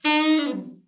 ALT FALL  16.wav